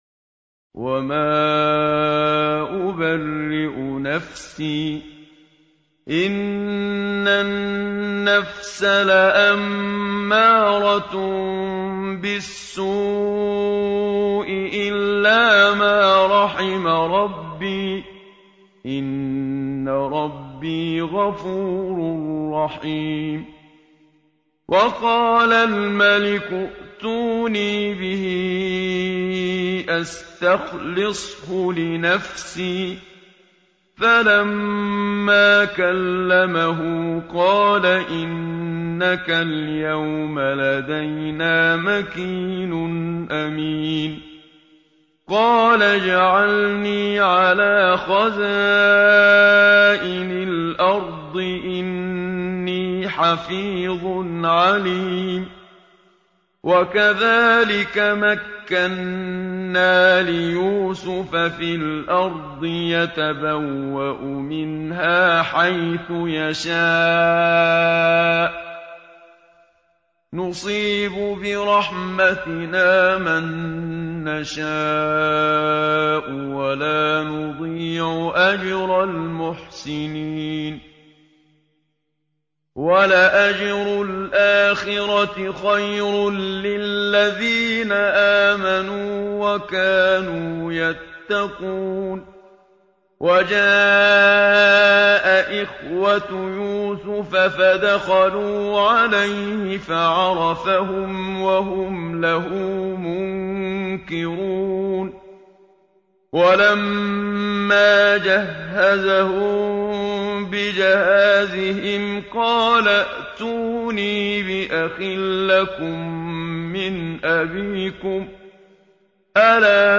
الختمة المرتلة بصوت الشيخ المنشاوي الجزء (13)
إذاعة طهران- الختمة المرتلة: تلاوة الجزء الثالث عشر من القرآن الكريم بصوت القارئ الشيخ محمد صديق المنشاوي.